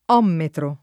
[ 0 mmetro ]